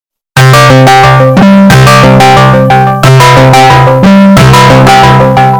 Sound Effects
Discord Call But Bass Boosted